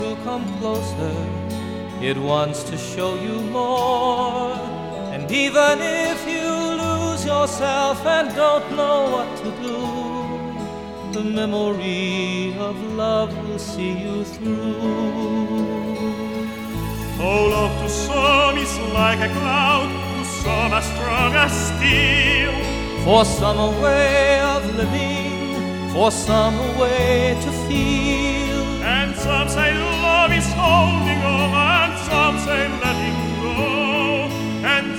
# Classical